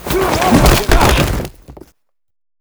Tackle3.wav